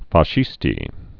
(fä-shēstē)